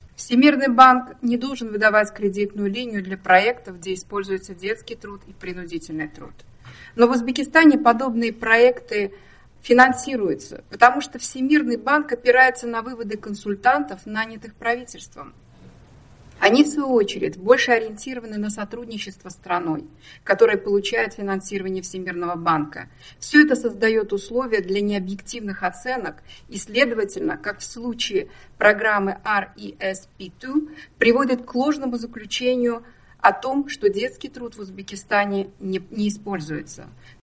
Vashingtondagi Jorjtaun universitetida uyushtirilgan anjumanda